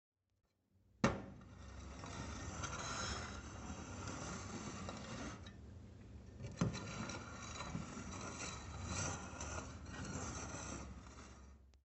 音频1 " 18 Mov Catapulta
描述：Foley Final Audio1 2018